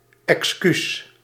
Ääntäminen
US : IPA : [ə.ˈpɑl.ə.dʒi]